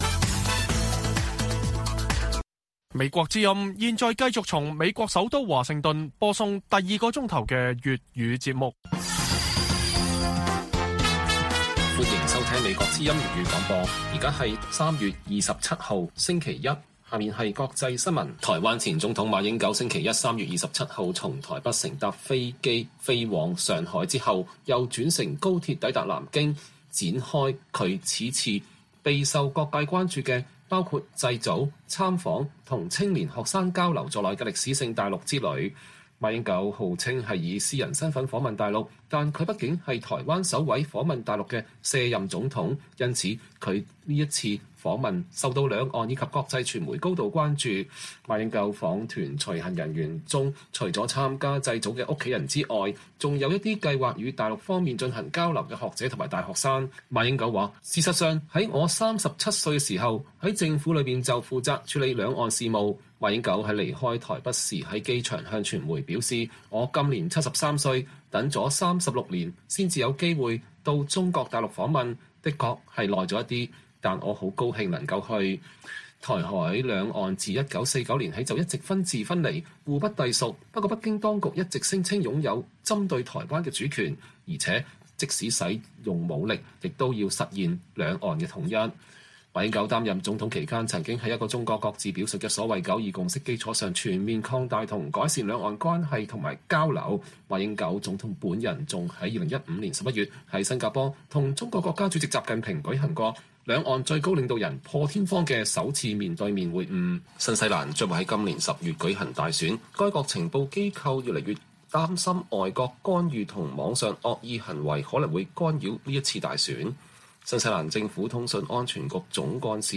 粵語新聞 晚上10-11點: 台灣前總統馬英九抵達中國訪問